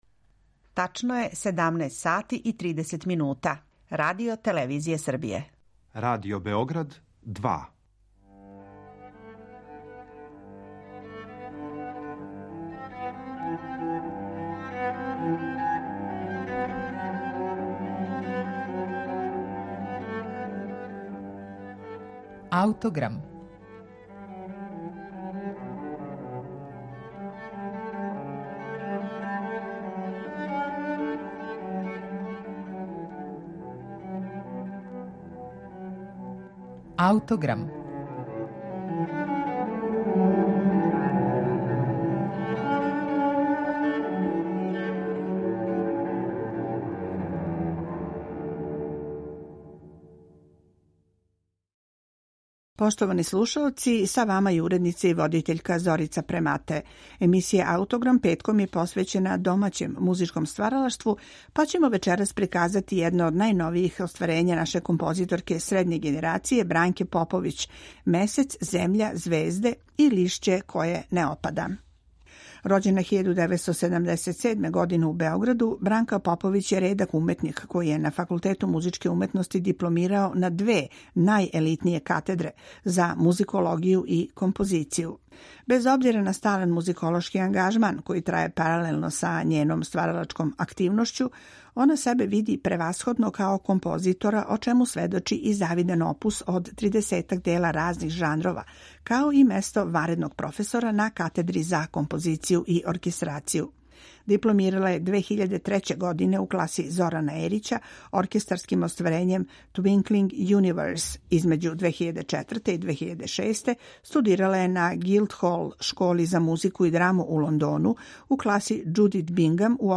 фантазију за баритон, кавал, чембало и електронику